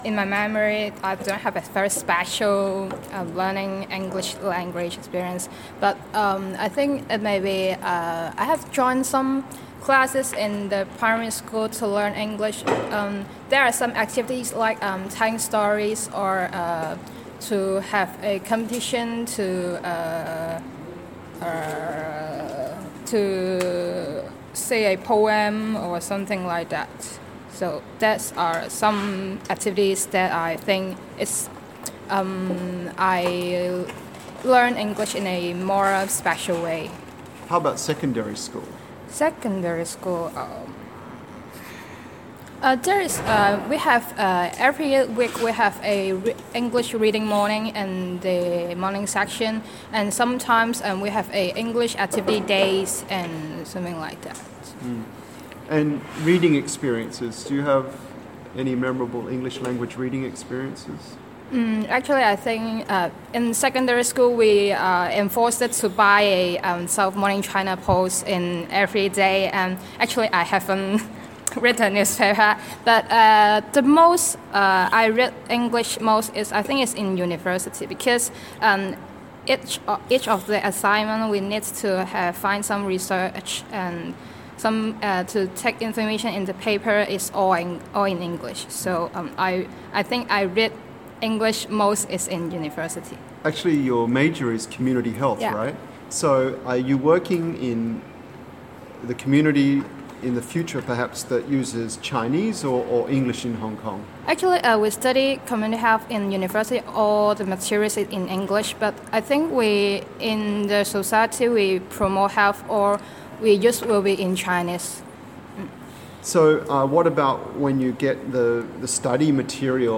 A community health major learnt English through activities such as poetry reading in primary school. She read the South China Morning Post in secondary school.